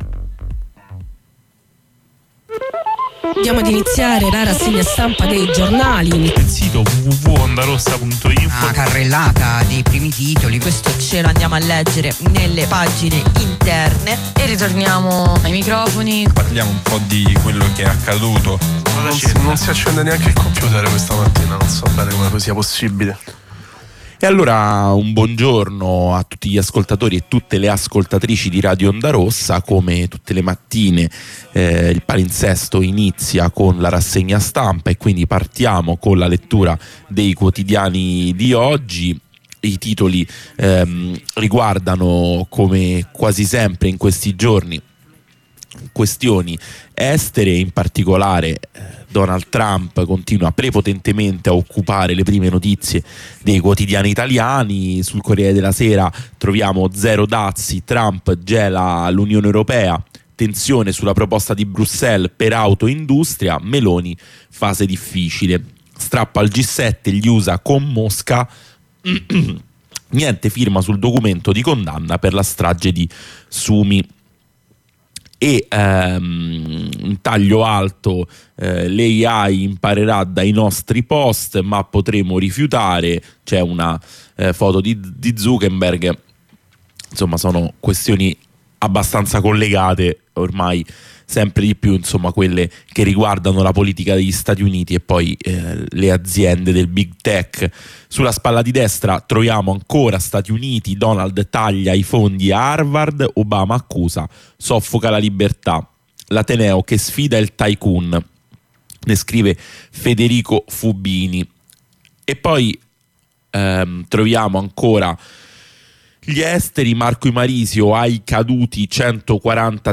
Lettura e commento dei quotidiani.